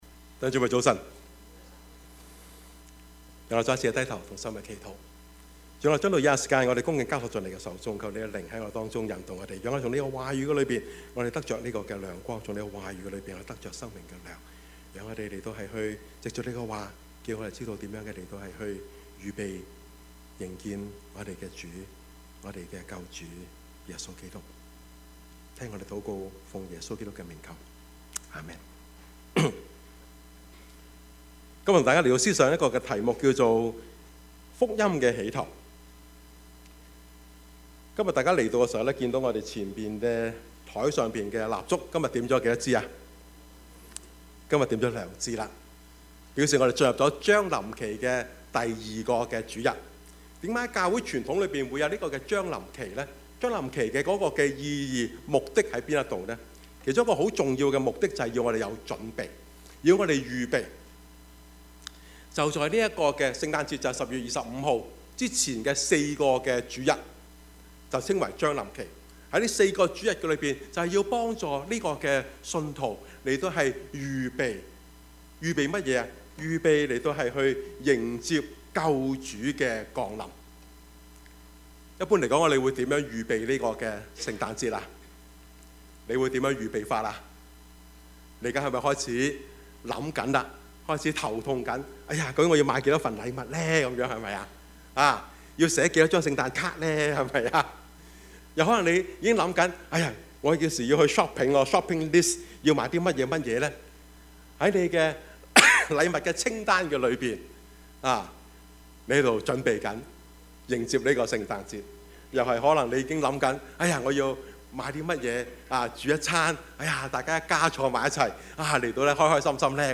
Service Type: 主日崇拜
Topics: 主日證道 « 要警醒！